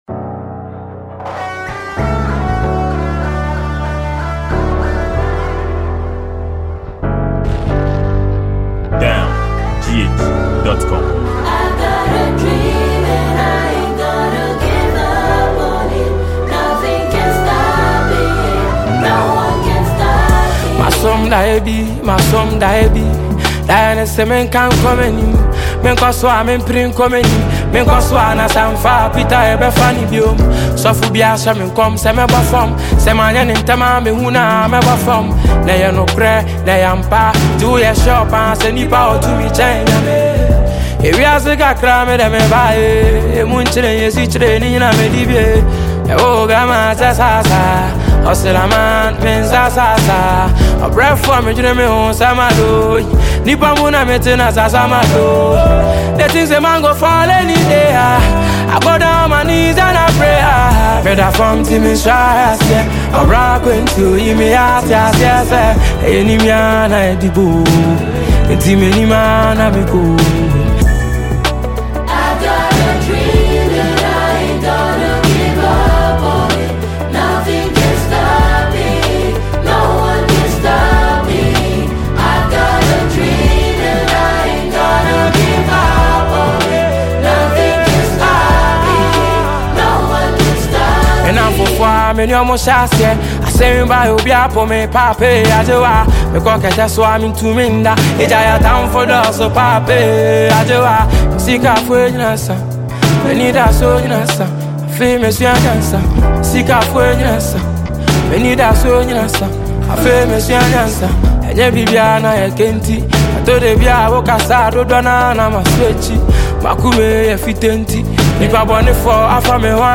Ghanaian highlife singer